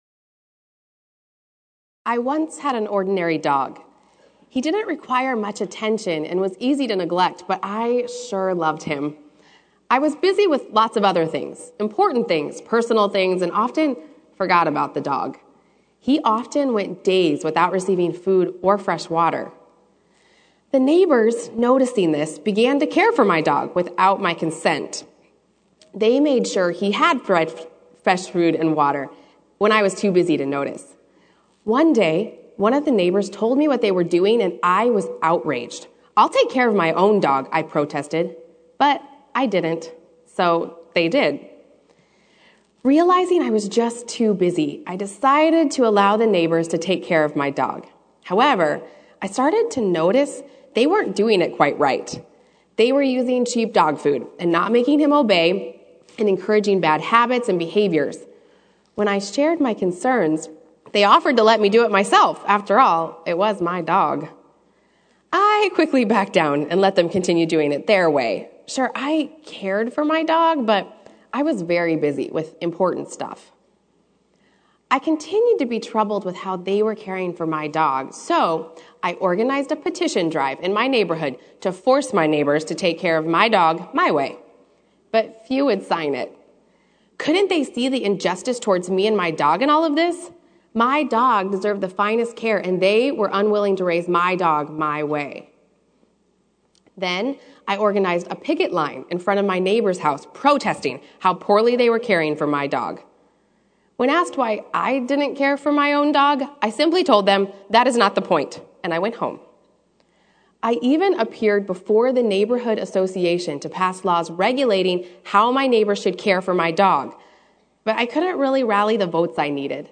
Sermon: Prepare to Meet Your God